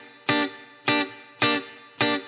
DD_TeleChop_105-Gmin.wav